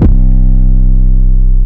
[808] (11) southside.wav